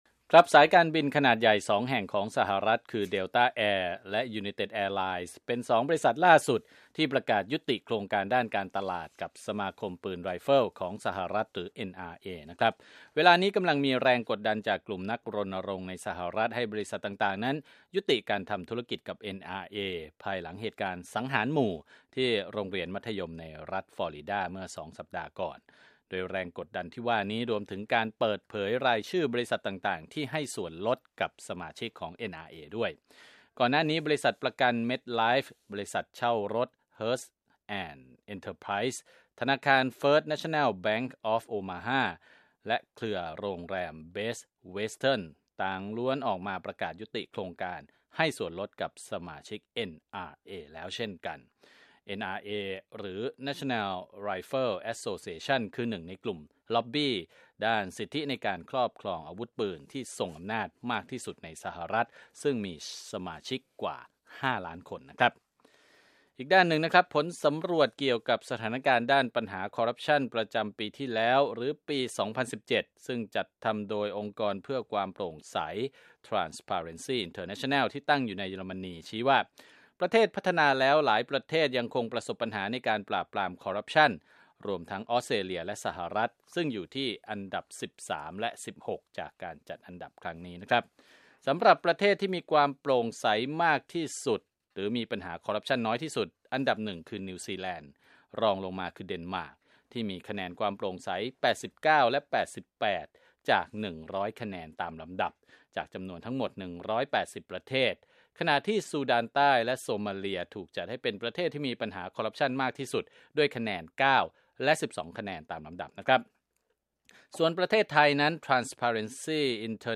ข่าวธุรกิจ 25 กุมภาพันธ์ 2561